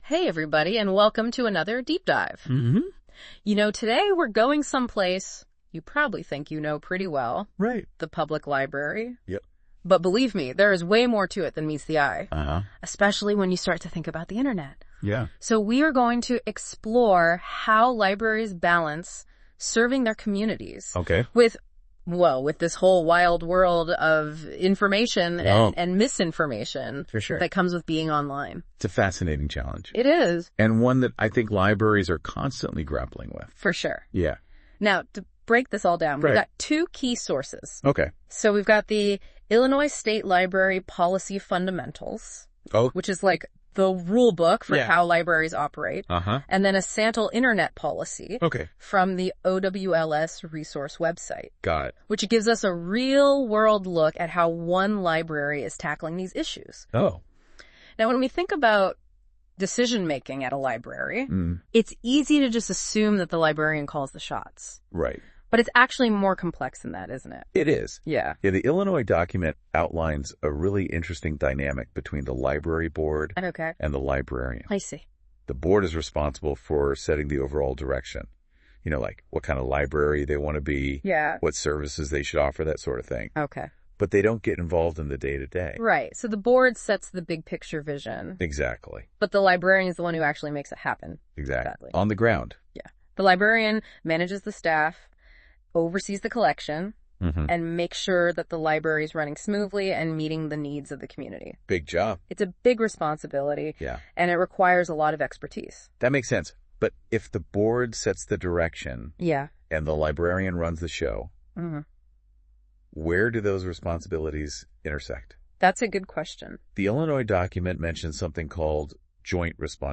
ai_generated_podcast-internet_usage_policy_2024.mp3